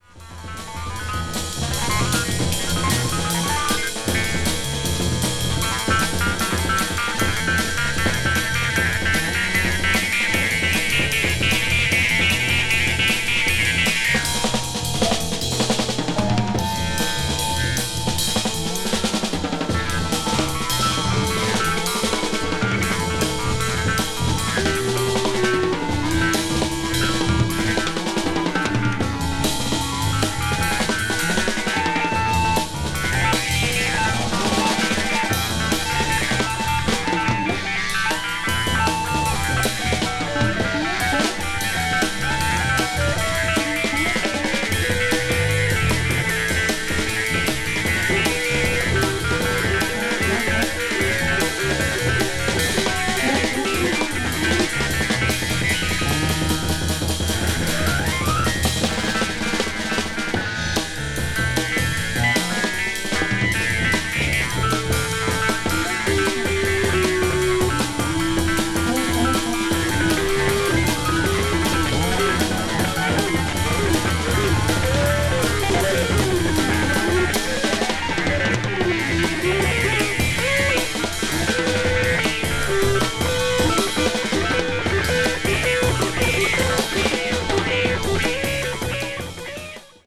a group of German and Dutch jazz musicians
avant-jazz   experimental jazz   jazz rock   spritual jazz